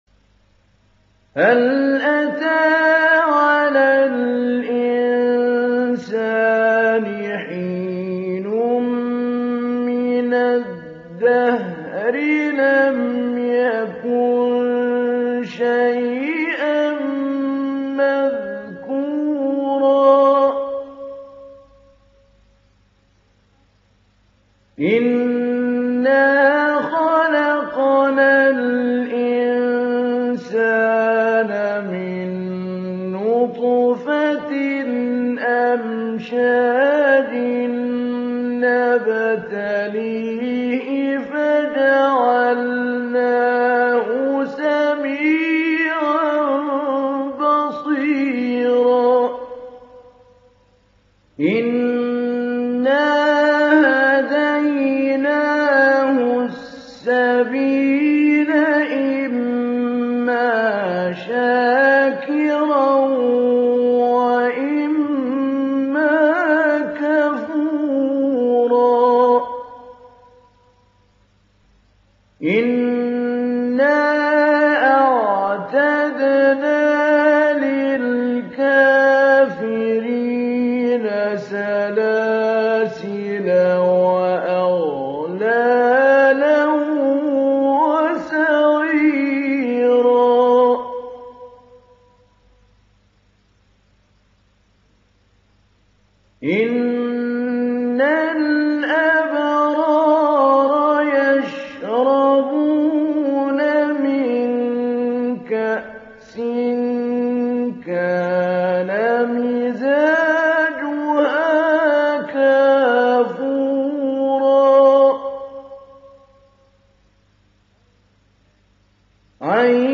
Insan Suresi İndir mp3 Mahmoud Ali Albanna Mujawwad Riwayat Hafs an Asim, Kurani indirin ve mp3 tam doğrudan bağlantılar dinle
İndir Insan Suresi Mahmoud Ali Albanna Mujawwad